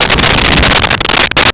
Sfx Crash Rock
sfx_crash_rock.wav